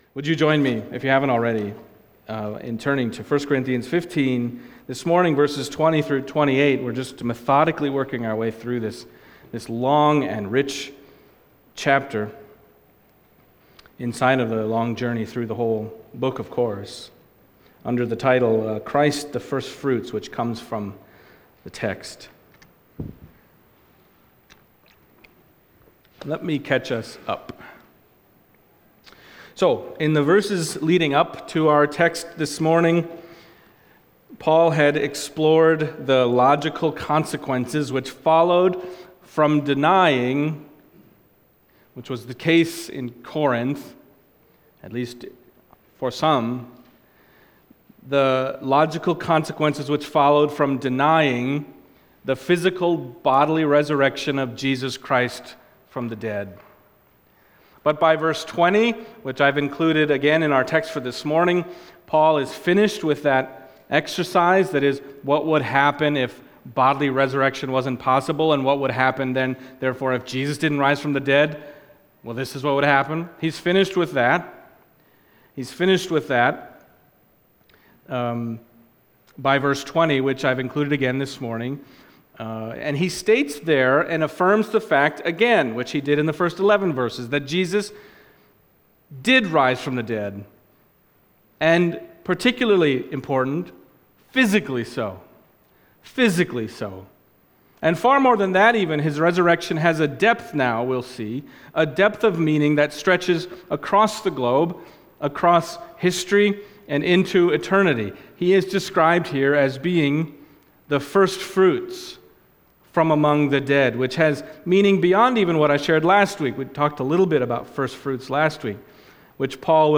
1 Corinthians 15:20-28 Service Type: Sunday Morning 1 Corinthians 15:20-28 « If Christ Has Not Been Raised…